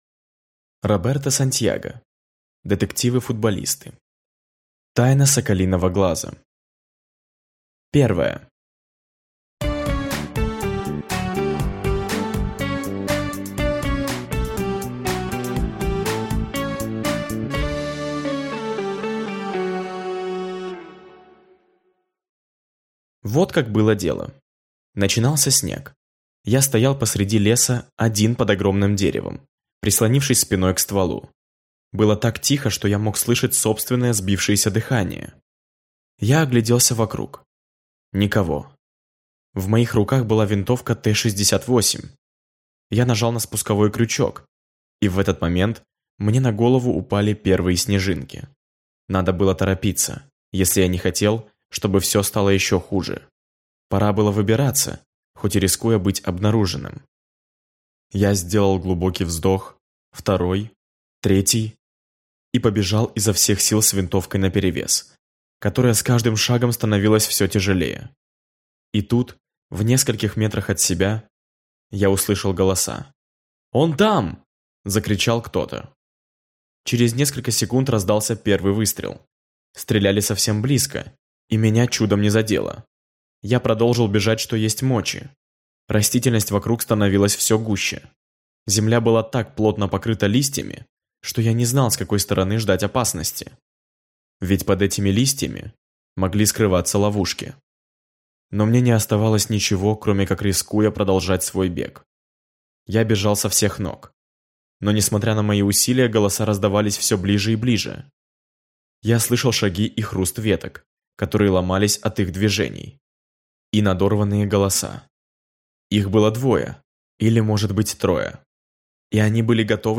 Аудиокнига Детективы-футболисты. Тайна Соколиного глаза | Библиотека аудиокниг